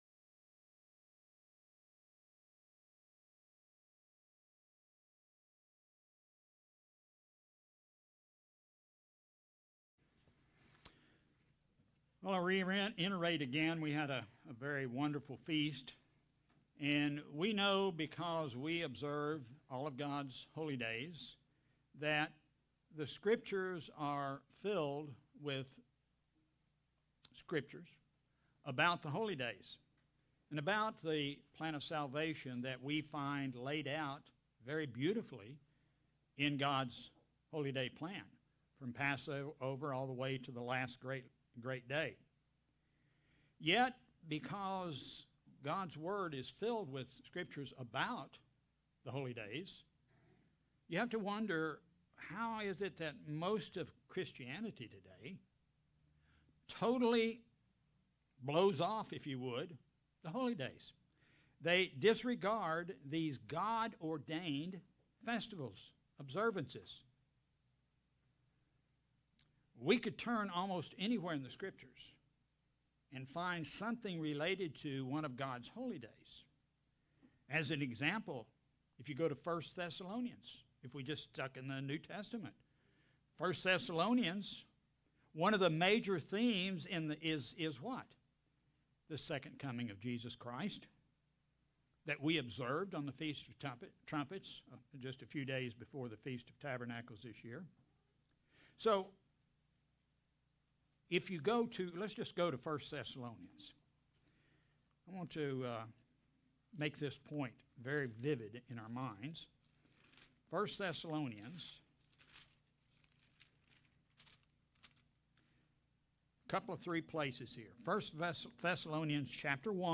Sermons
Given in Springfield, MO